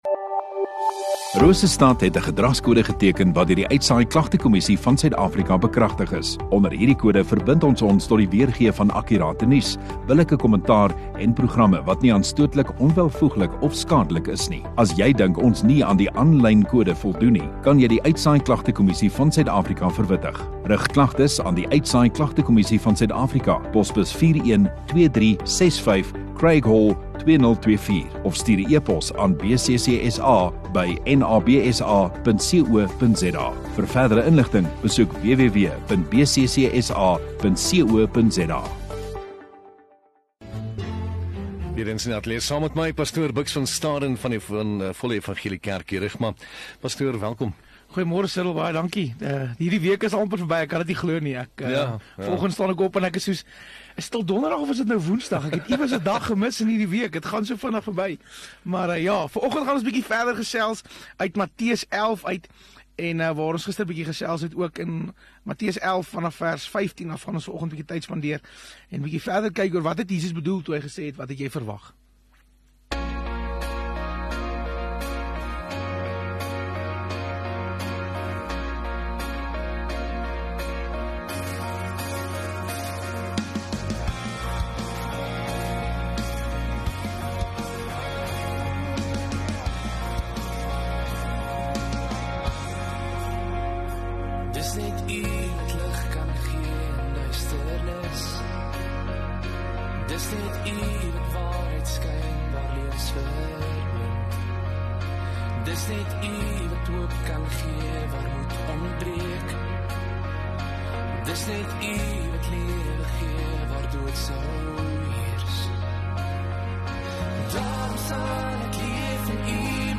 12 Oct Donderdag Oggenddiens